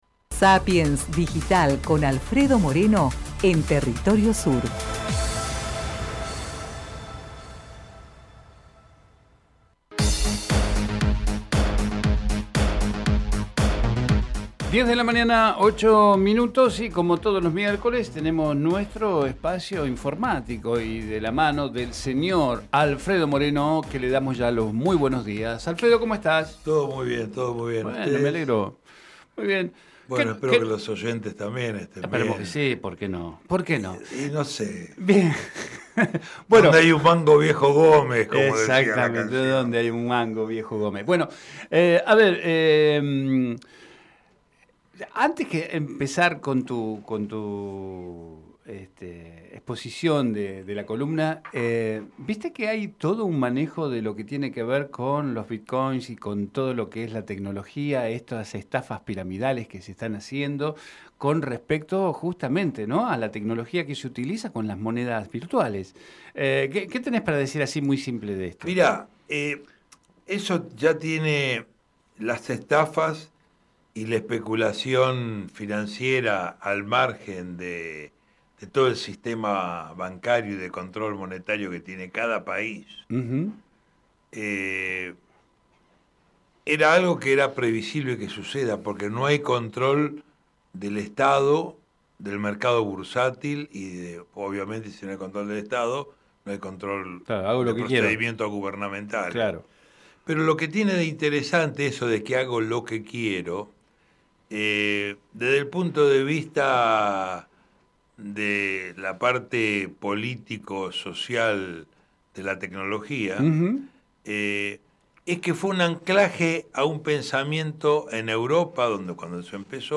Compartimos con ustedes la entrevista realizada en Territorio Sur